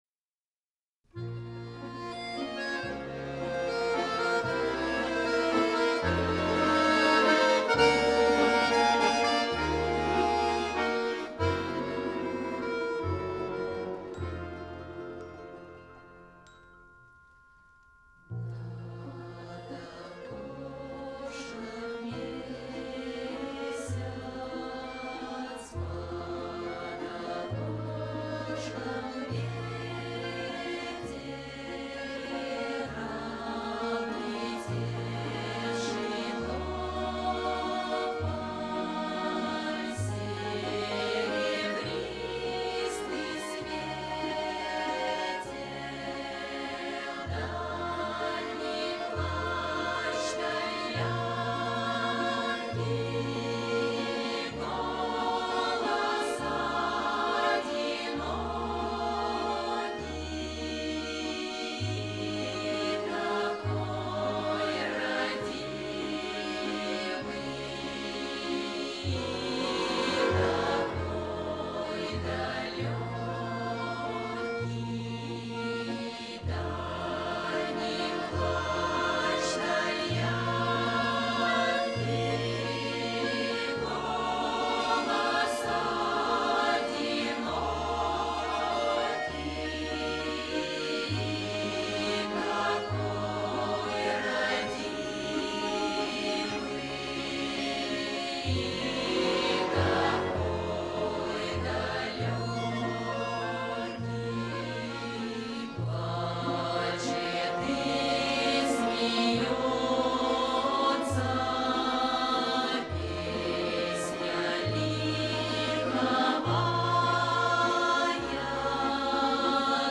Рязанский русский народный хор - Над окошком месяц.mp3